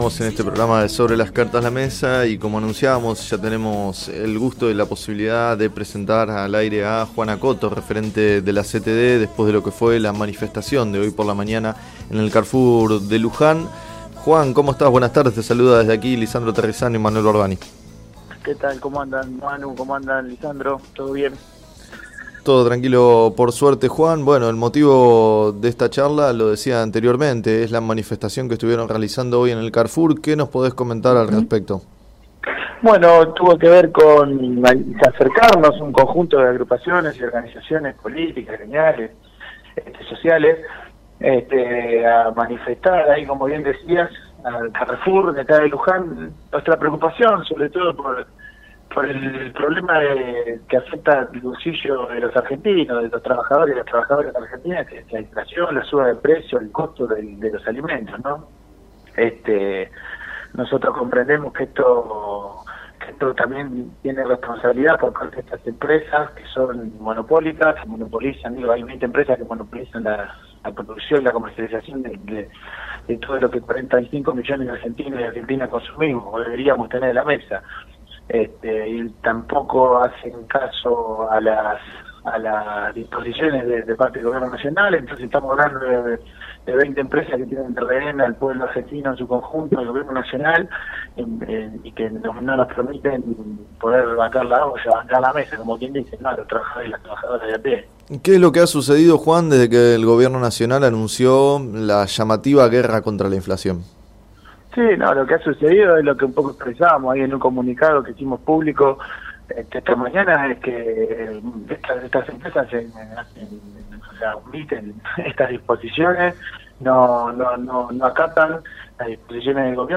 En declaraciones al programa “Sobre las cartas la mesa” de FM Líder 97.7